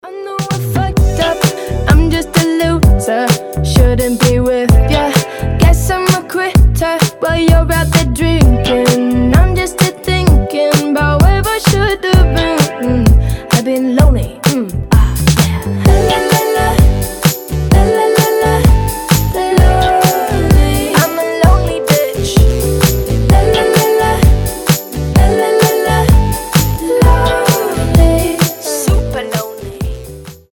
• Качество: 320, Stereo
женский голос
indie pop
alternative
озорные